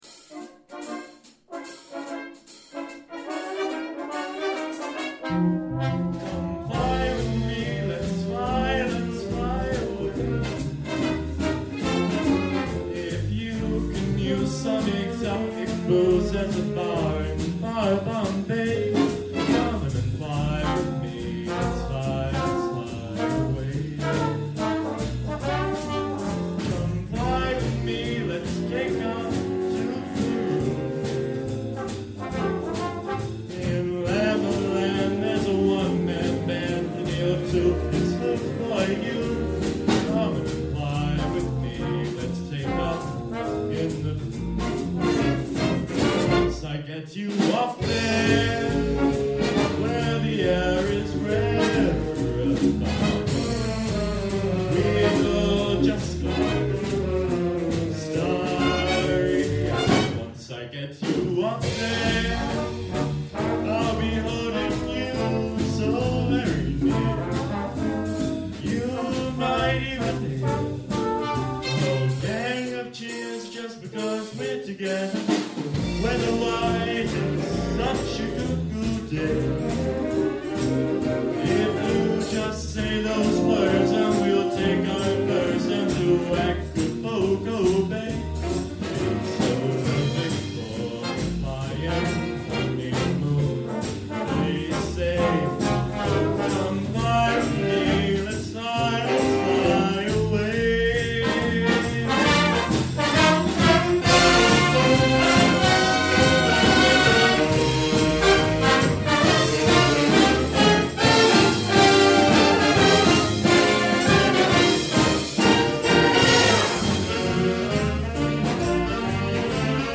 Come Fly With Me - Big Band Nov 2018